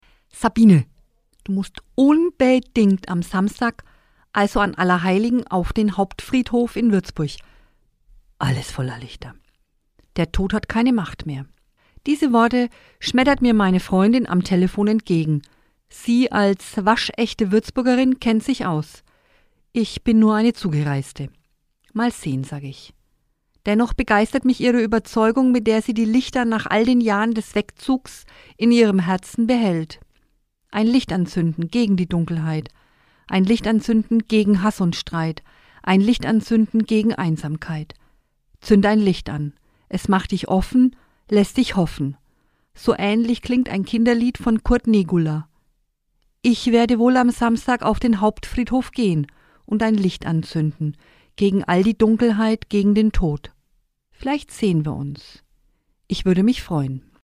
Autorin und Sprecherin ist